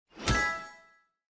star_sound2.mp3